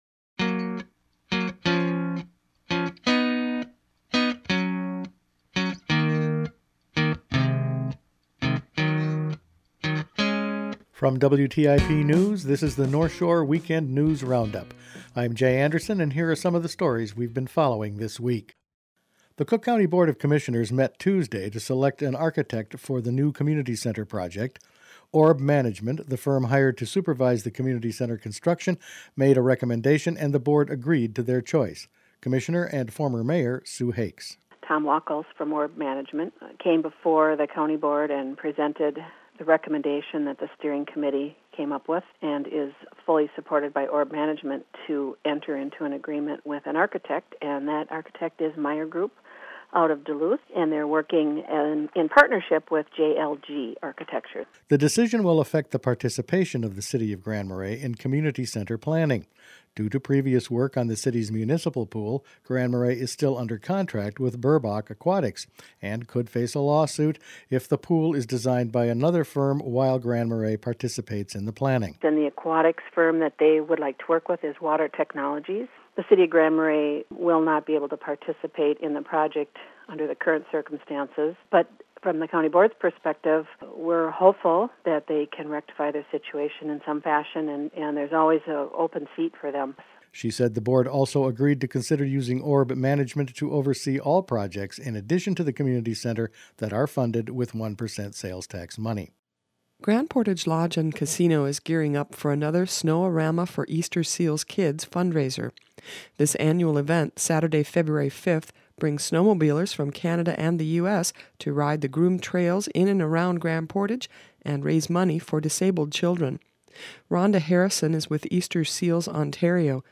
Weekend News Roundup for Jan. 29